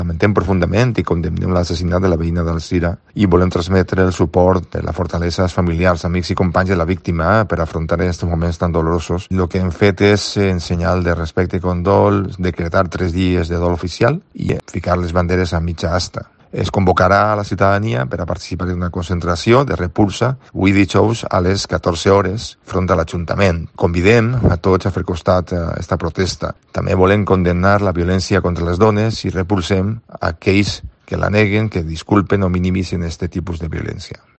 Alfons Domínguez, alcalde de Alzira, declara tres días de luto